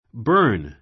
bə́ː r n バ ～ン